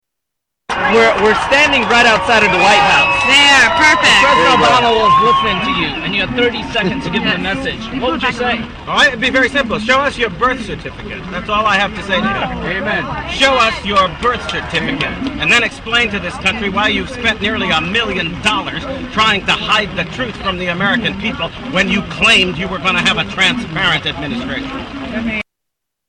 Tags: Political Alan Keyes audio Alan Keyes Alan Keyes Speeches The Tea Part